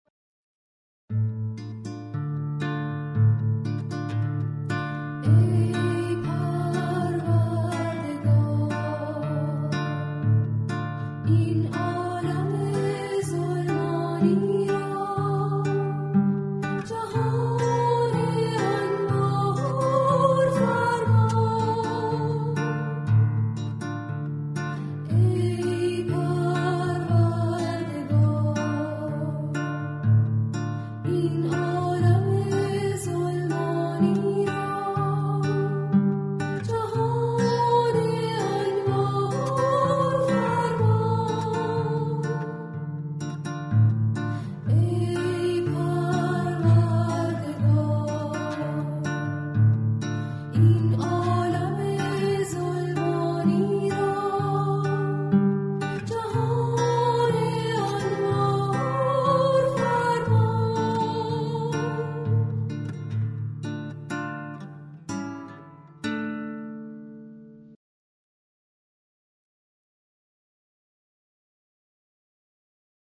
دعا و نیایش با موسیقی